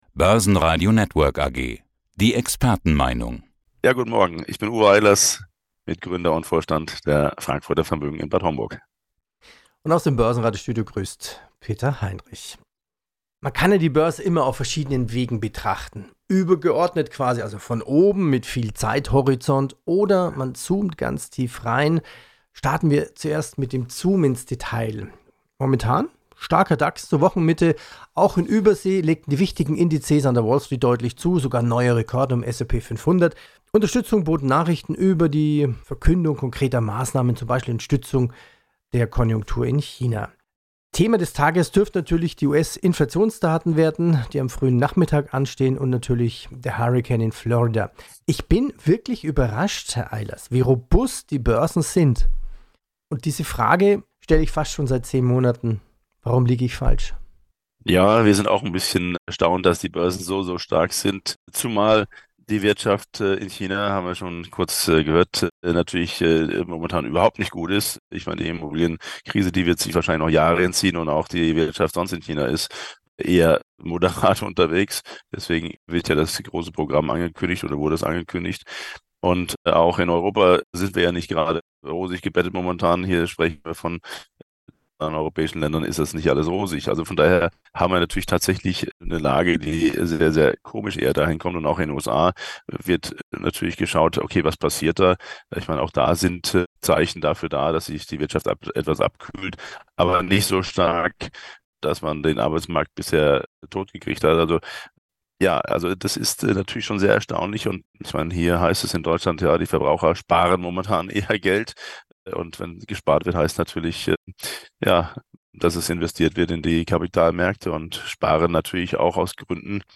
Radio report: Hurricane season in the USA – an economic stimulus programme? – Bureaucracy to blame for recession.